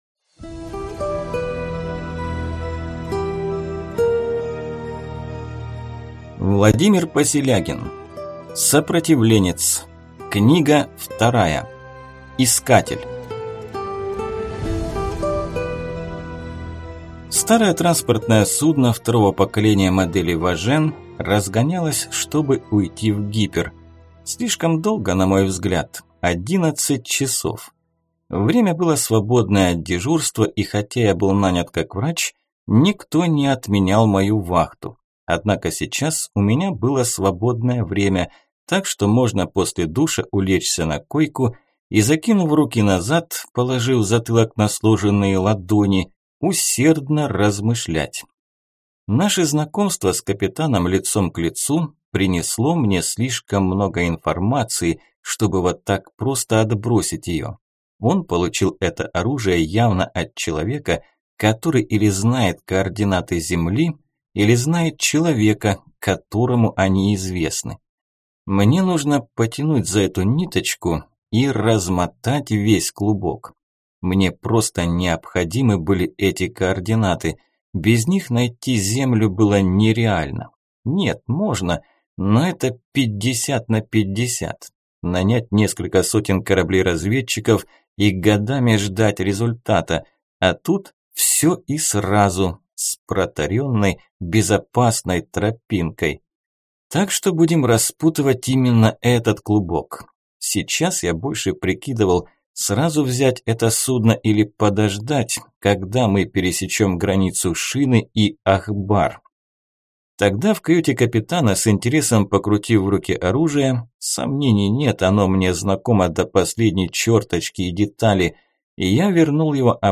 Аудиокнига Искатель | Библиотека аудиокниг